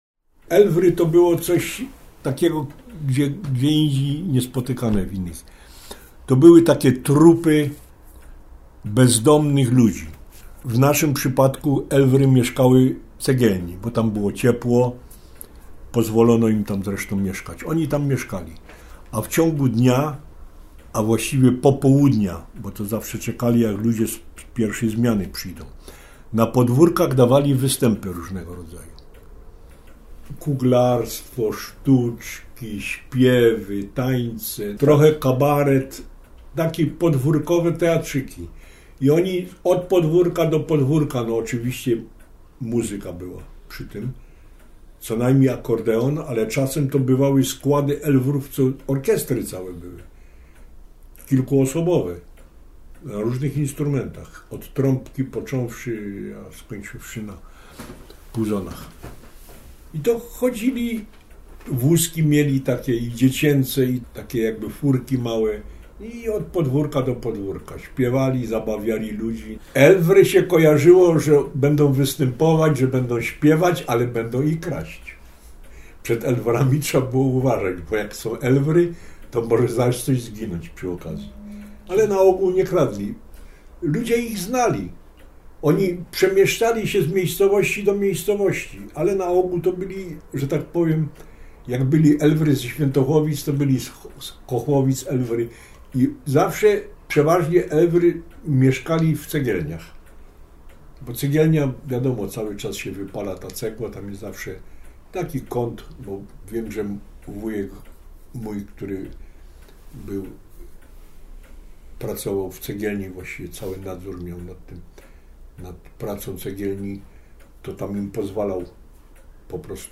Relacja mówiona zarejestrowana w ramach Programu Historia Mówiona realizowanego w Ośrodku